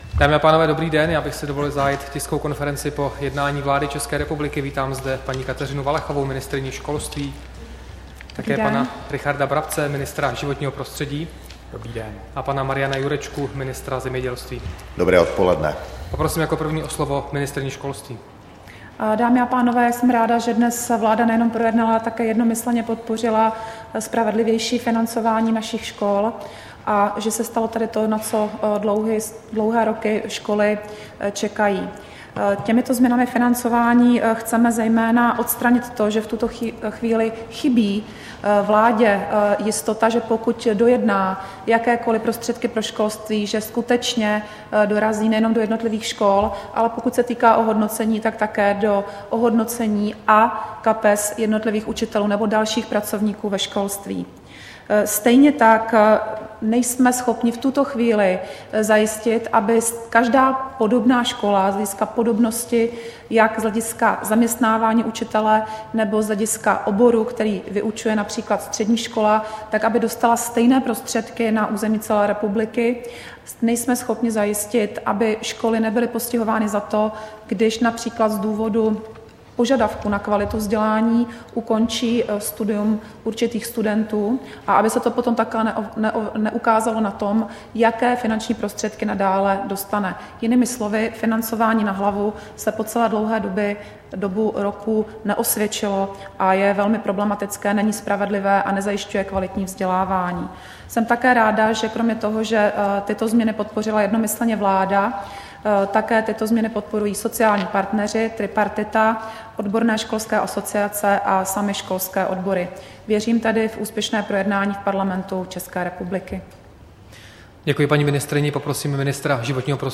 Tisková konference po jednání vlády 15. června 2016